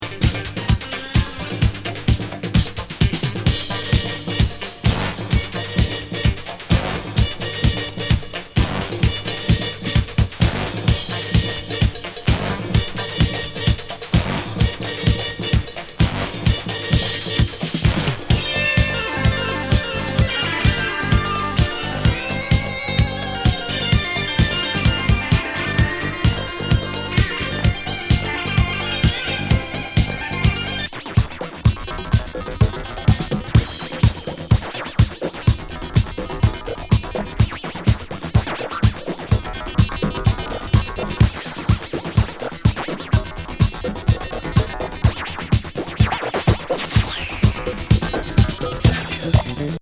太いキックにアホいボーカルでズンズン進む前のめり
ムニョムニョピュンピュン鳴ってるシンセサイザーがなんともカッコいい！！